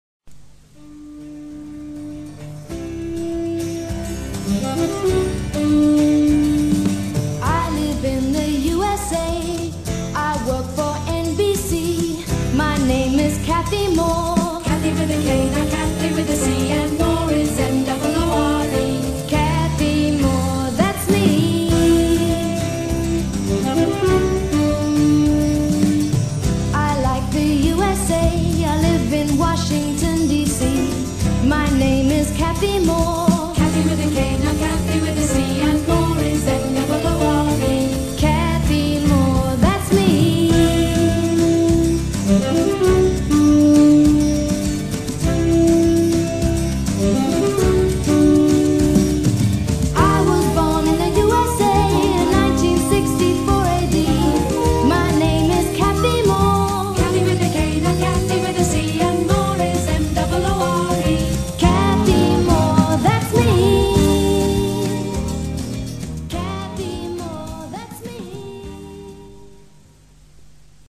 Aprende Gramática Cantando
con esta canción no comercial